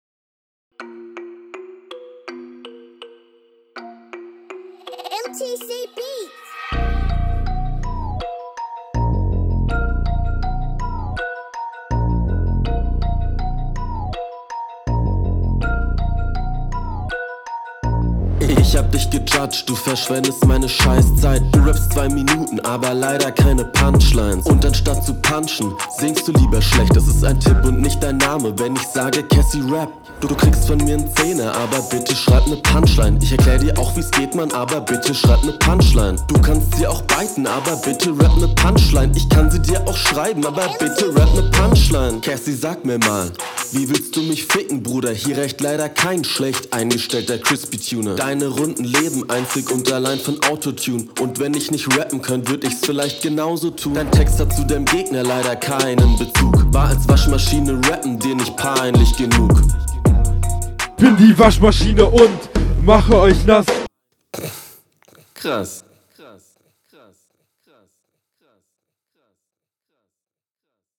Flow: Du rappst ziemlich gelangweilt.
Flow: Er trifft halt den Takt aber das wars, besonders ist der Flow nicht, einfach …